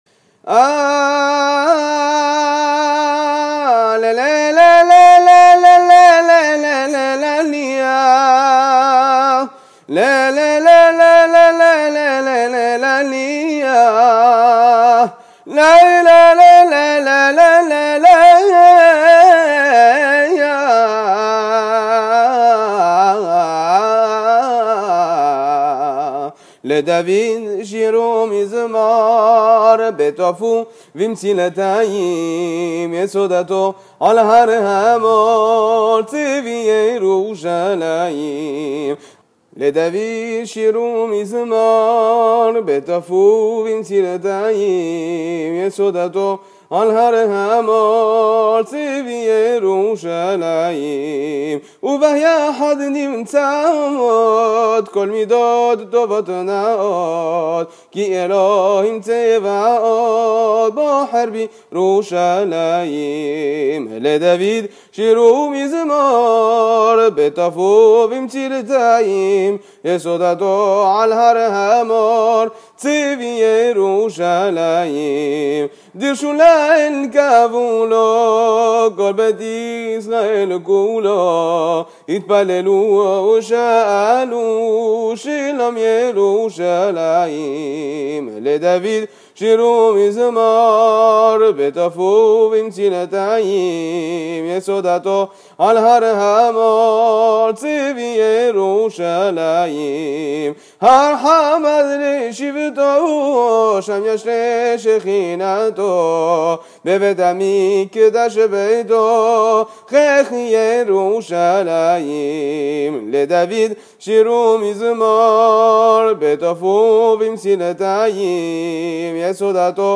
Nigoun du Maroc. Makam Sahli לְדָוִד שִׁיר וּמִזְמוֹר בְּתֹף וּבִמְצִלְתַּי
Hazanout